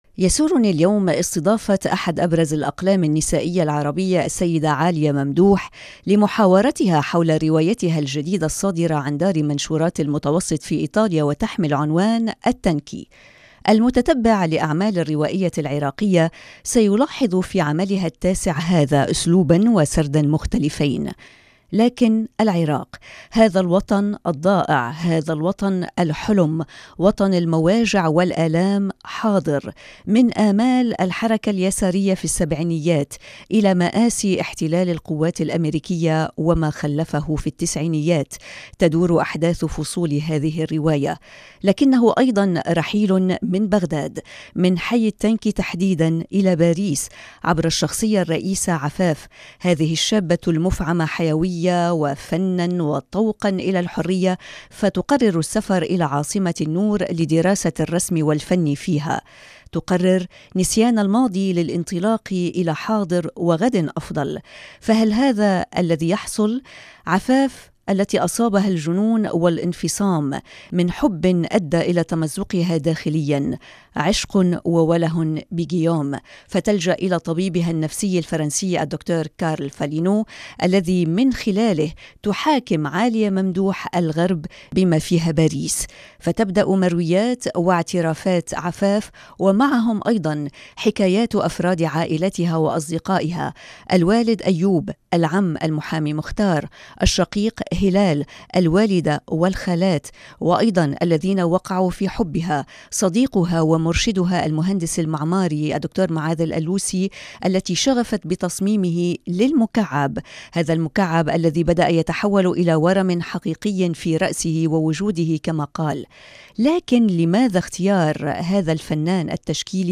حوار مع مونت كارلو حول رواية التانكي تاريخ حزيران يونيو 2019
مقابلات